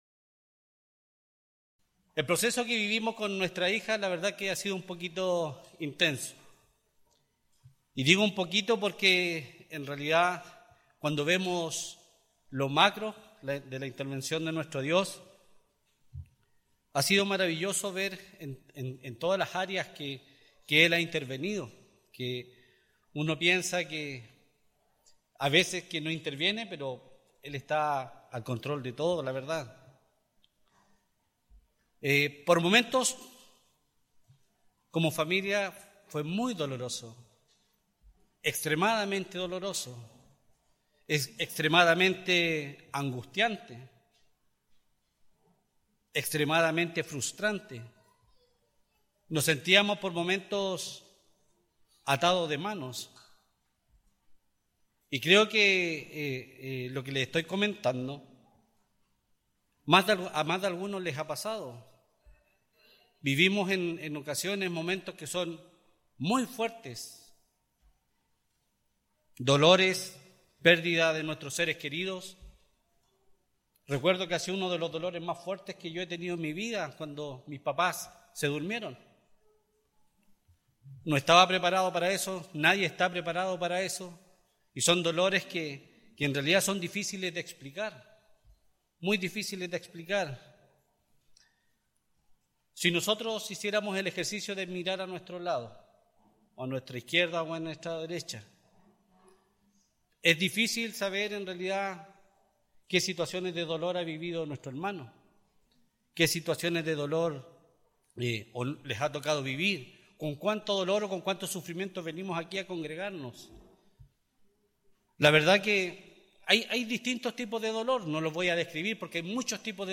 En este sermón aprenderemos el porqué del sufrimiento.